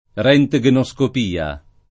rHjgenSkop&a; meno bene, alla ted., r£ntgenSkop&a] (meno com. roentgenscopia [id.]) s. f. (med.)